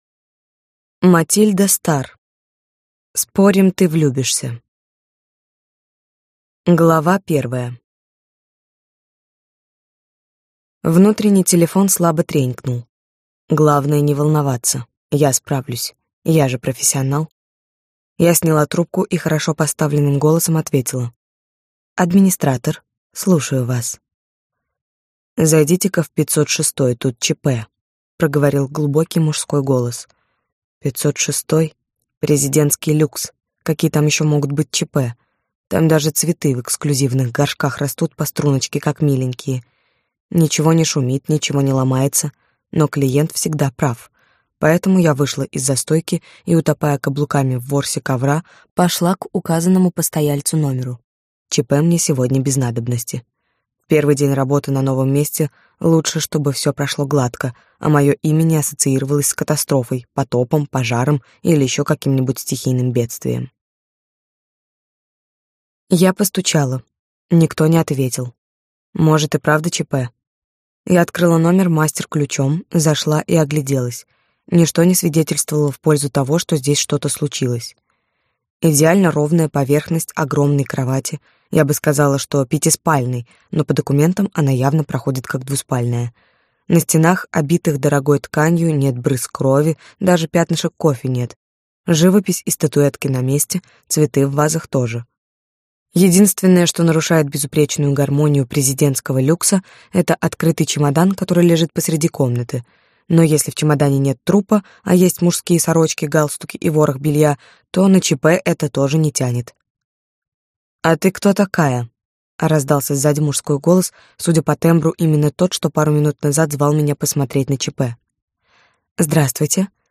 Аудиокнига Спорим, ты влюбишься?